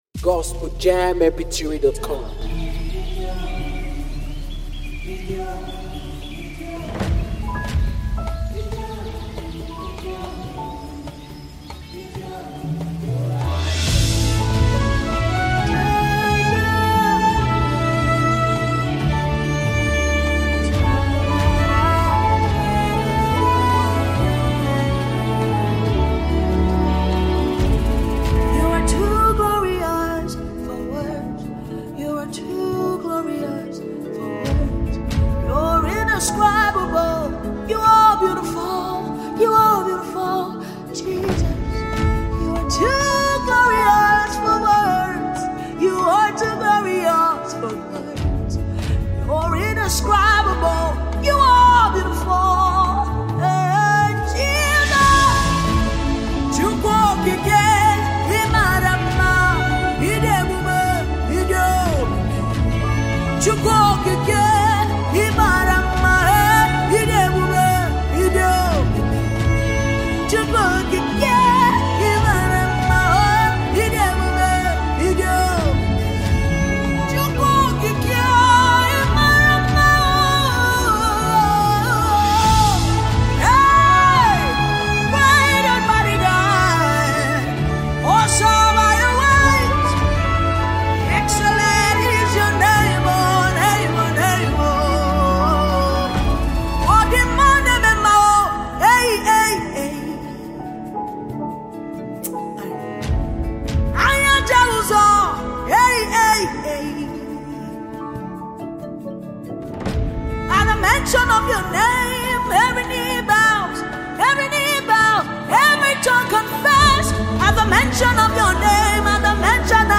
a powerful and reverent gospel song